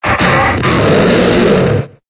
Cri de Trioxhydre dans Pokémon Épée et Bouclier.